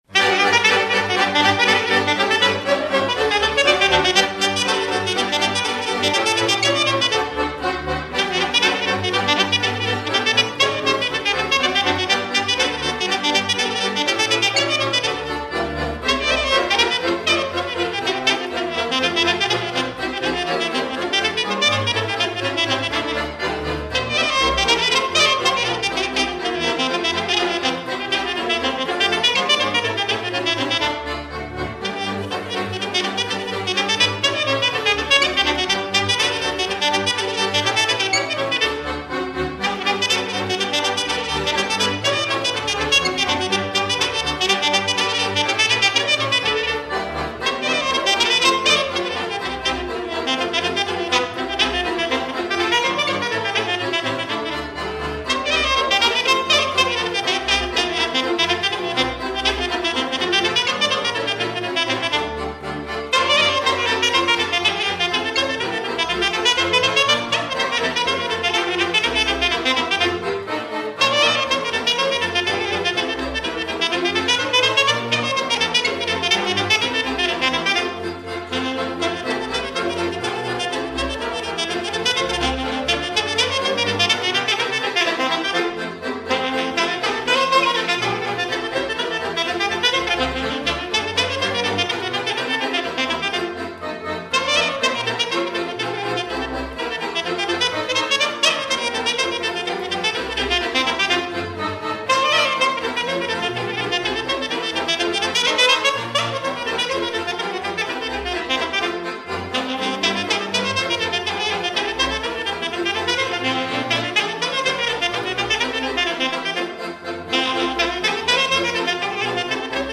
Jocuri populare moţeşti 3:01
taragot